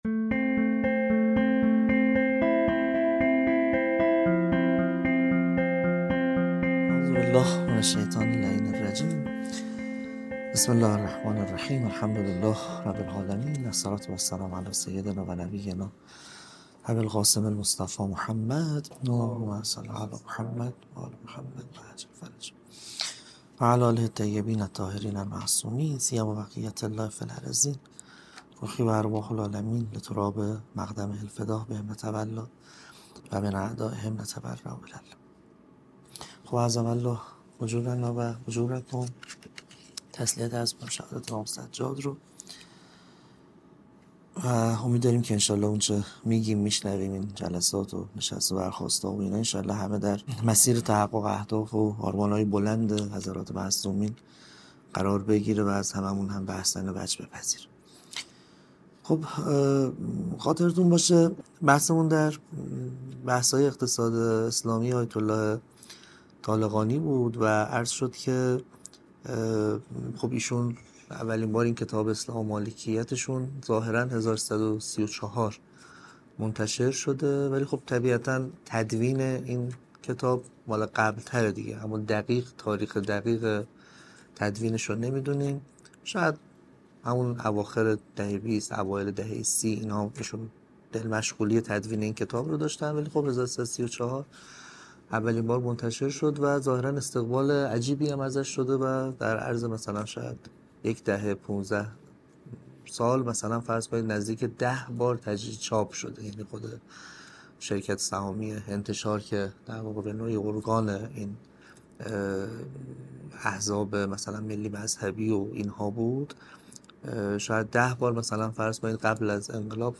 روایت اول از سلسله درس‌گفتار‌های تاریخ اقتصاد اسلامی معاصر- ترم دوم